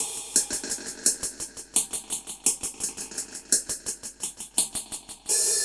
Db_DrumsA_HatsEcho_85_02.wav